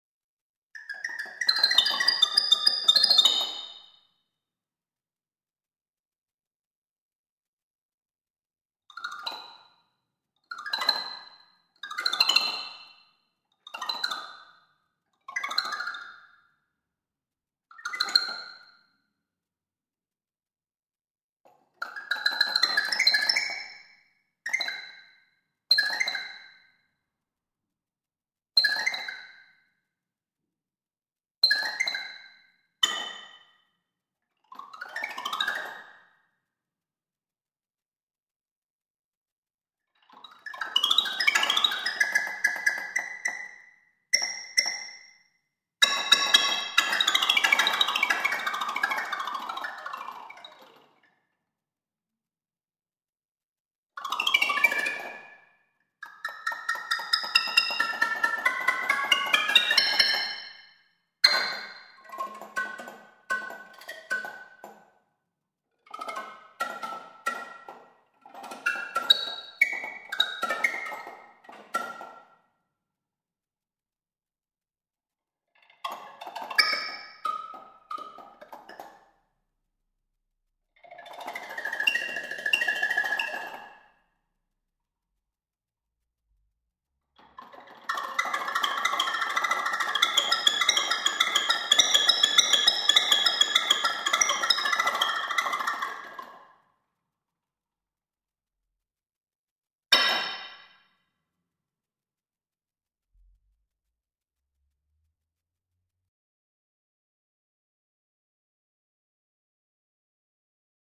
robot  blazen/lucht  midi  orgel
muziekrobot